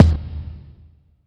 stacke_kick_2.wav